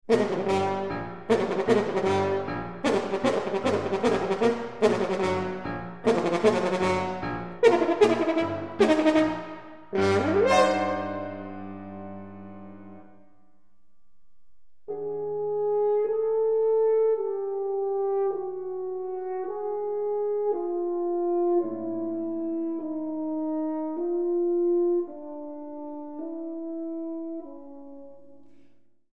Horn
Piano
Iwaki Auditorium, ABC Southbank, Melbourne